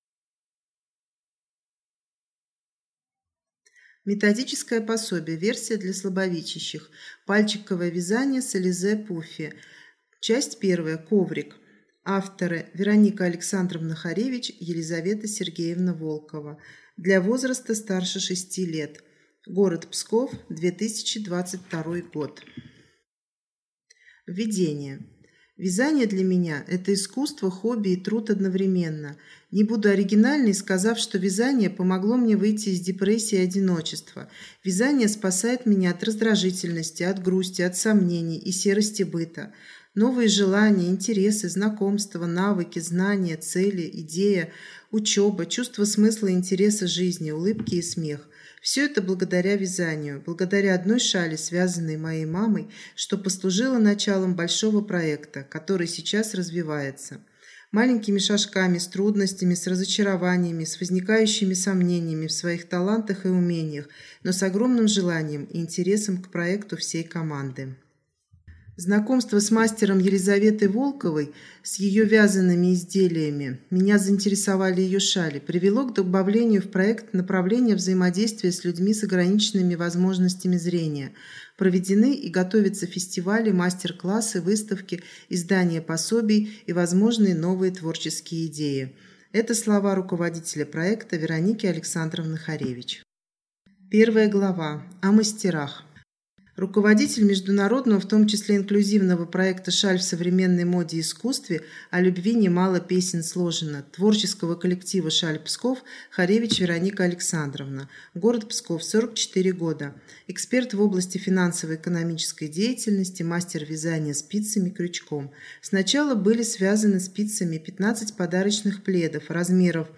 Аудиокнига Пальчиковое вязание с Ализе Пуффи. Часть I: коврик.